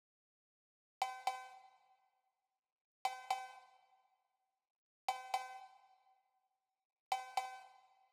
12 Electric Cowbell.wav